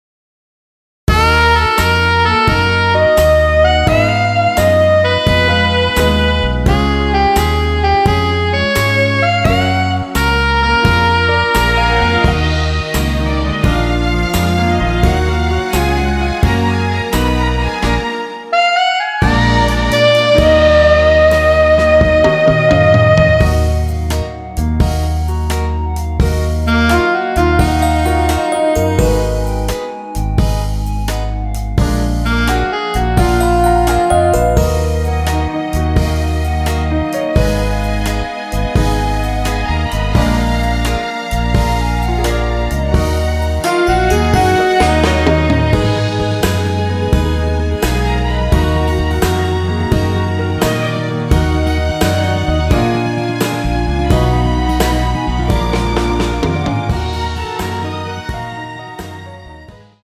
원키 멜로디 포함된 MR입니다.(미리듣기 확인)
Ebm
음정과 박자 맞추기가 쉬워서 노래방 처럼 노래 부분에 가이드 멜로디가 포함된걸
앞부분30초, 뒷부분30초씩 편집해서 올려 드리고 있습니다.
중간에 음이 끈어지고 다시 나오는 이유는